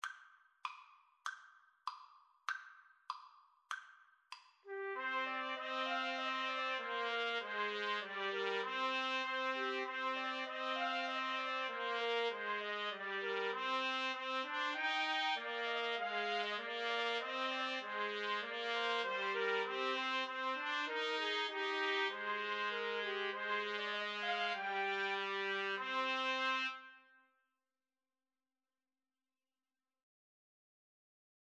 Christmas Christmas Trumpet Trio Sheet Music
Trumpet 1Trumpet 2Trumpet 3
2/4 (View more 2/4 Music)